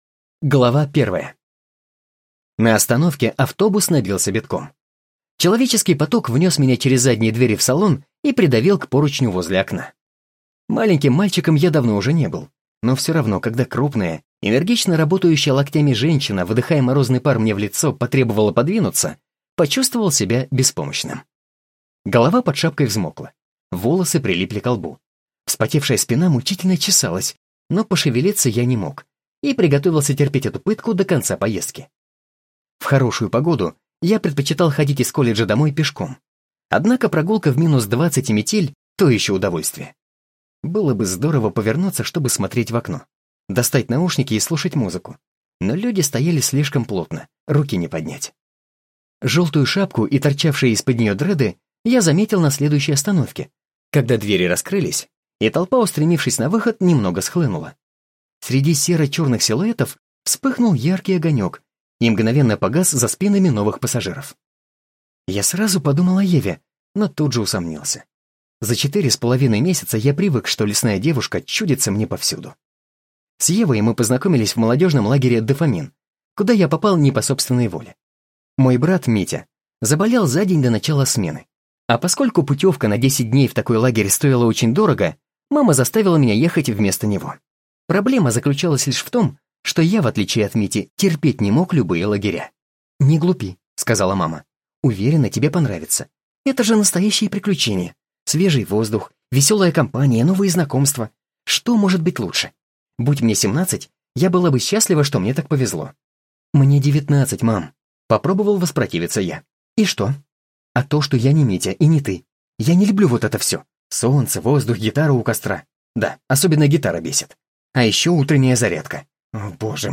Диктор, актёр озвучивания, у микрофона более пяти лет.
Молодой, энергичный, если нужно романтичный голос.
Тракт: Микрофон SE Electronics X1 S, звуковая карта Arturia MiniFuse 2, акустически оборудованное помещение (домашняя студия).